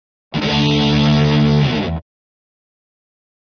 Guitar Heavy Metal Finale Chord 2